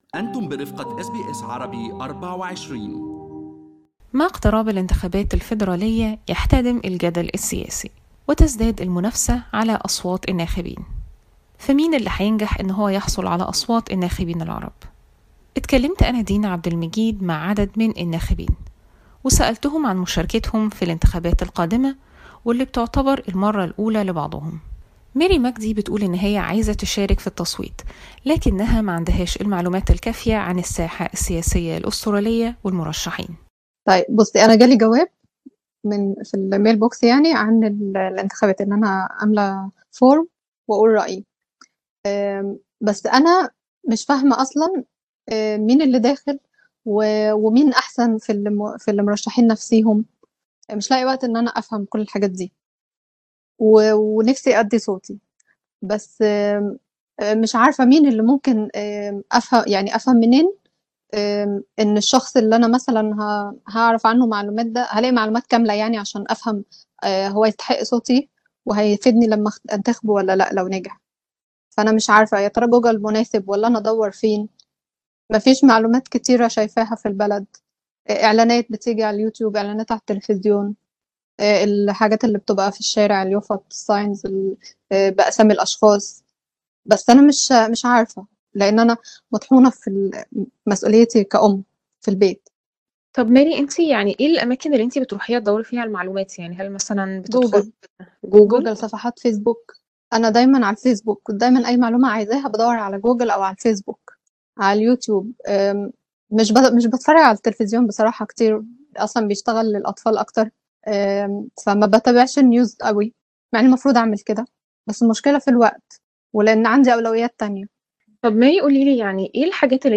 arab_voters_report_web.mp3